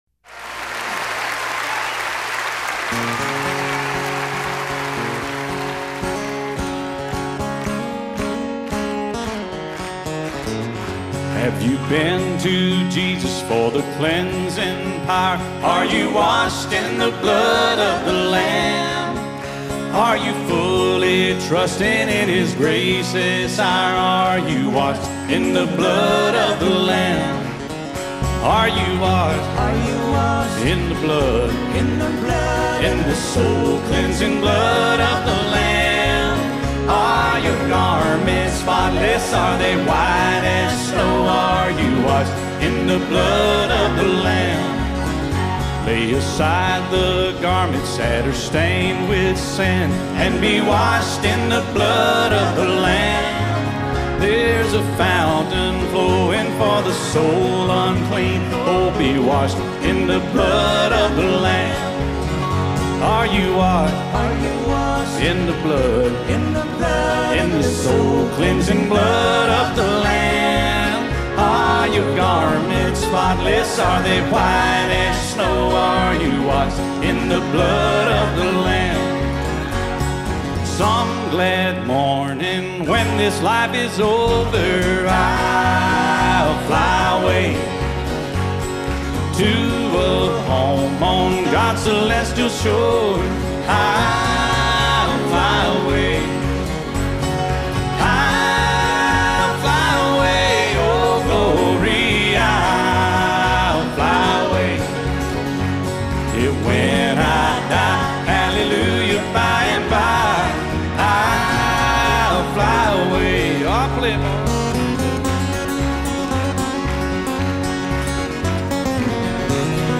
Concert in a church